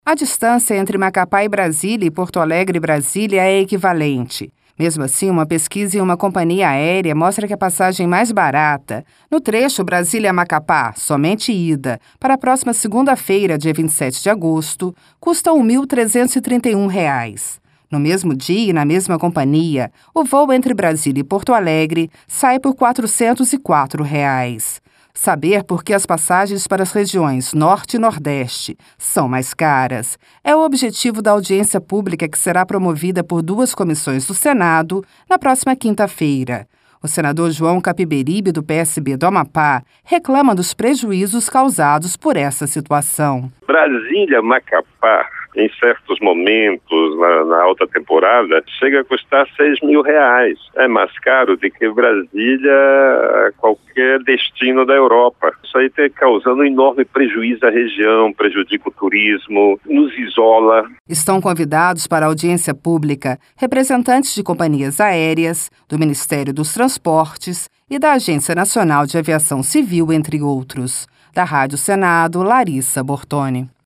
Senador João Capiberibe